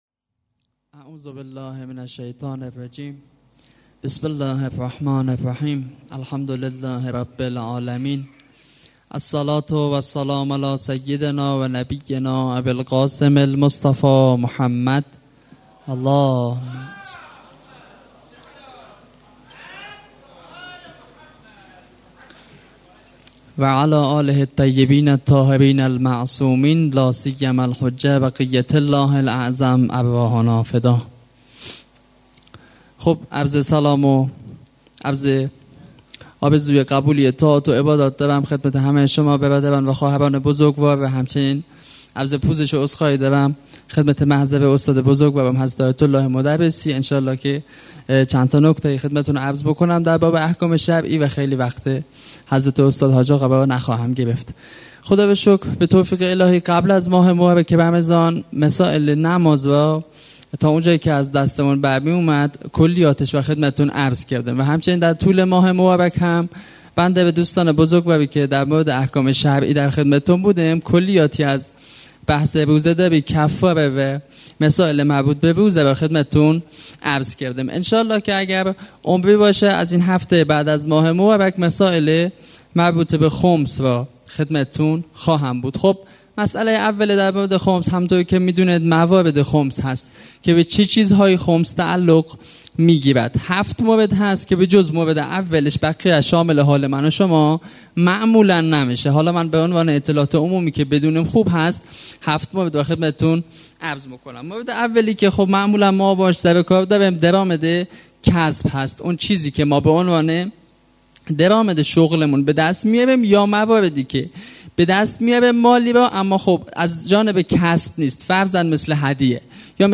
قرار هفتگی مسجد جامع یزد منبر احکام خمس